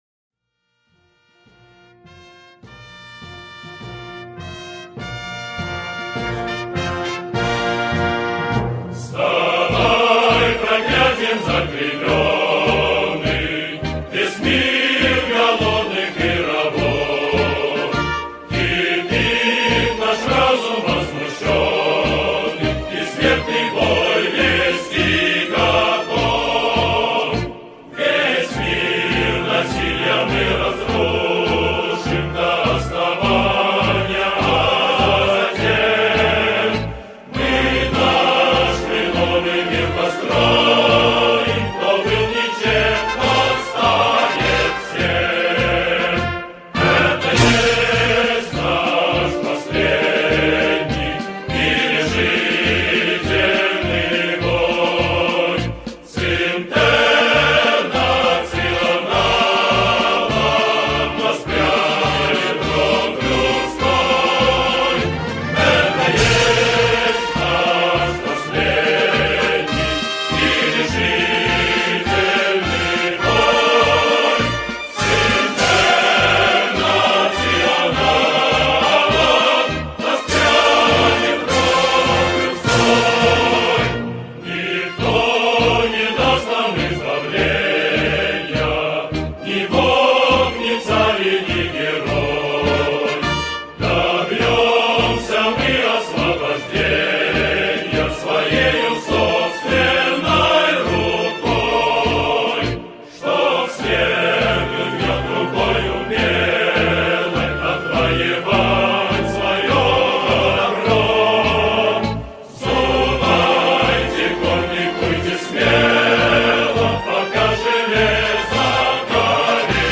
гимн